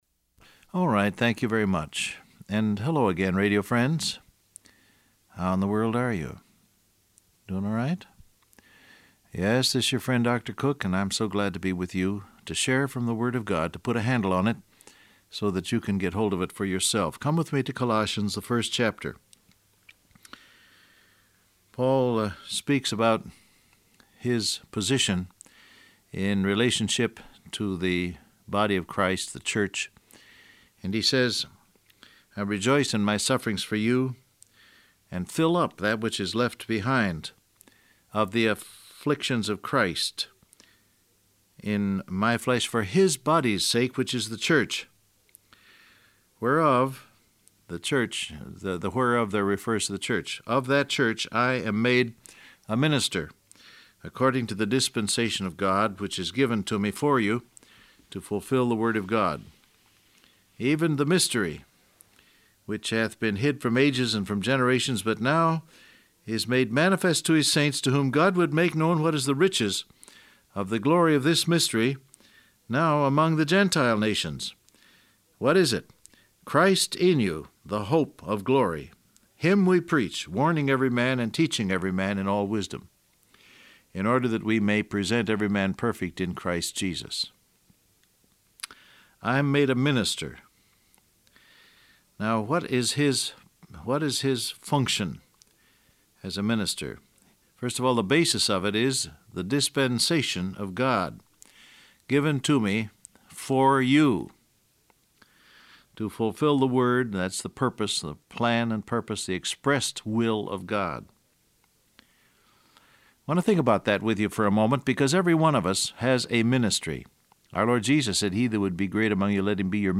Download Audio Print Broadcast #1870 Scripture: Colossians 1:24-25 , 1Peter 1:18-19 Transcript Facebook Twitter WhatsApp Alright, thank you very much.